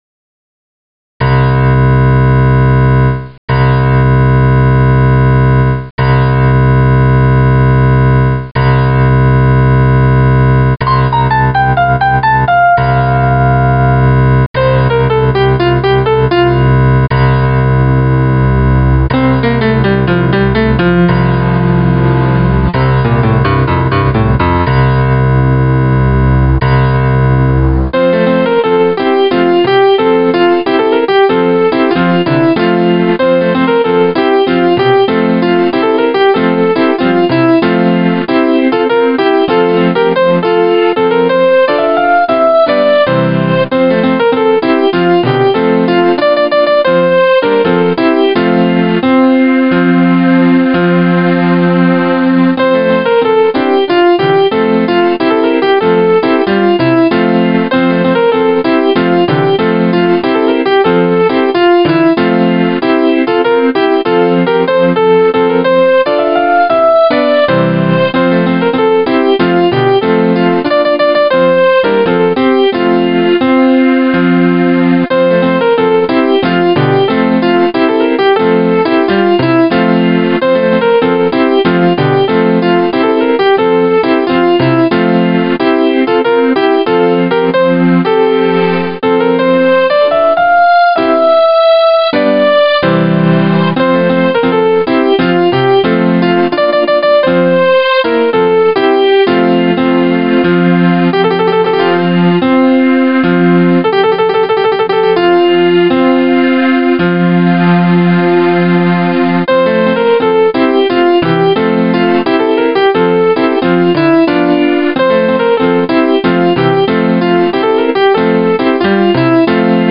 Voices used: Grand piano and strings